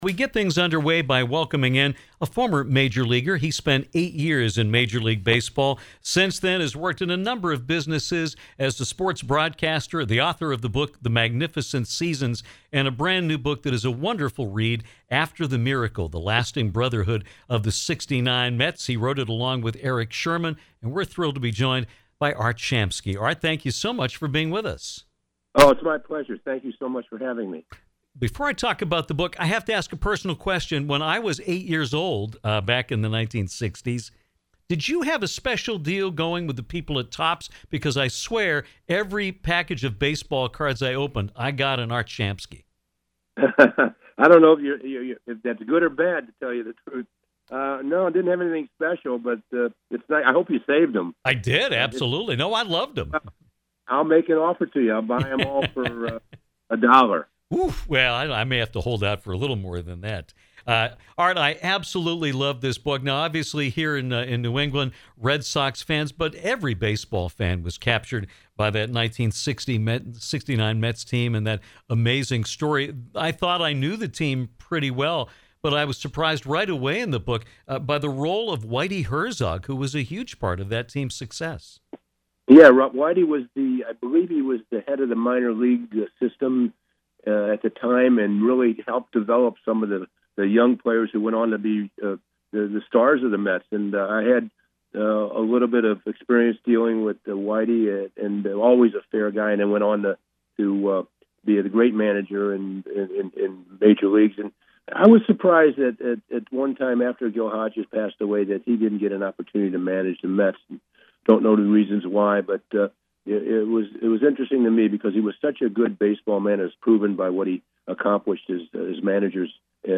Former major leaguer Art Shamsky talked with us about his new book, “After The Miracle”, a look back at the Miracle Mets of 1969 and a poignant reunion of some of the members of that remarkable team.